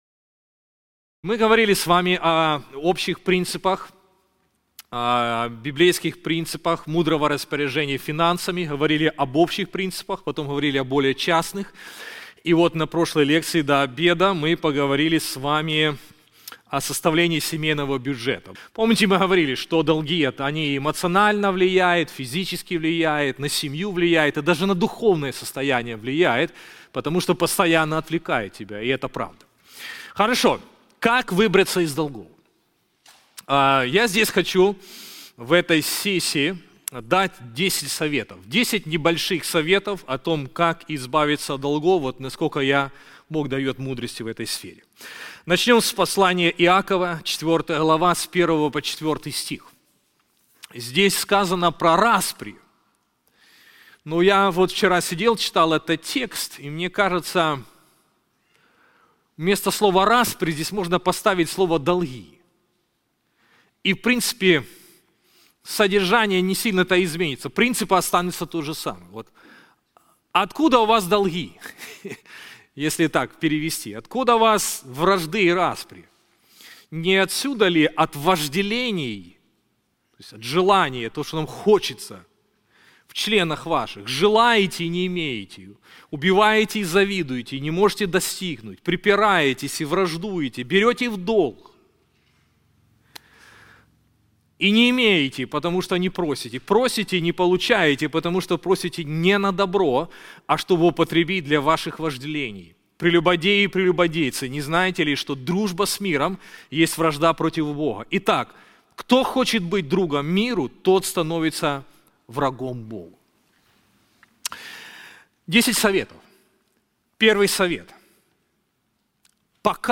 Мы подготовили специальный семинар о библейских принципах управления финансами.